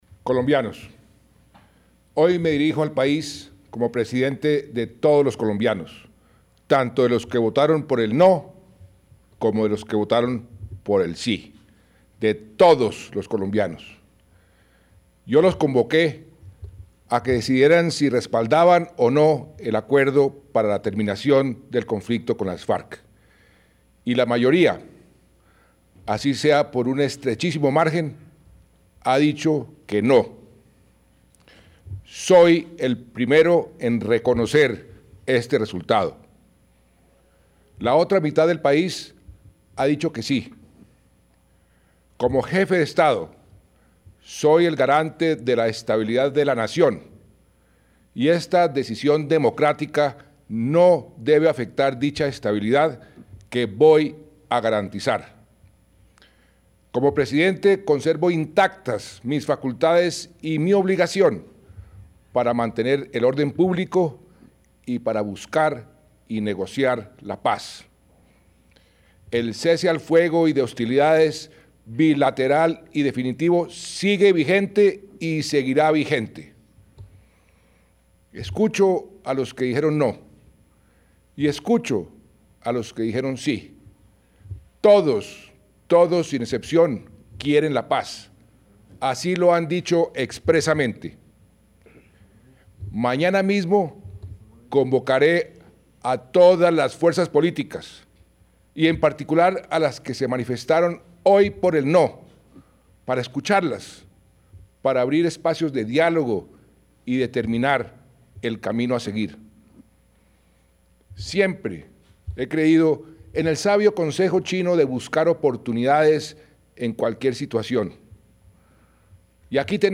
Colombia, Bogotá
Discursos